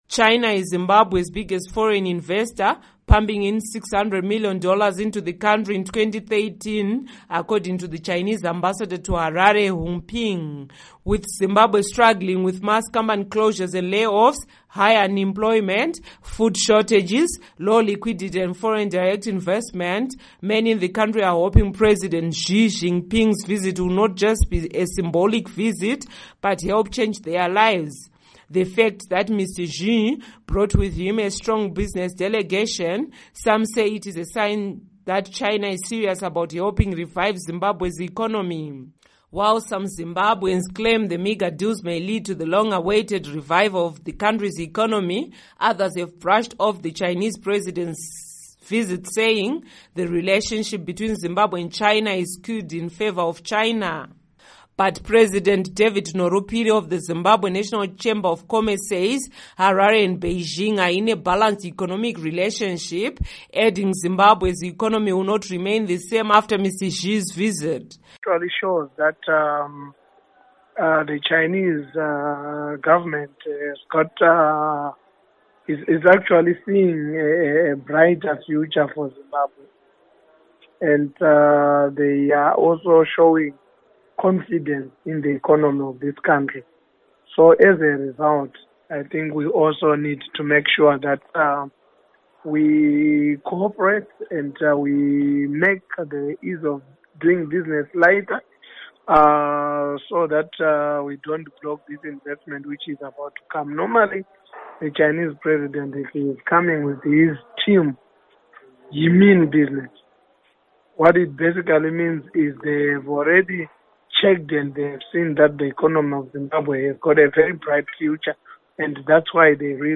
Report on Zimbabwe, China Relations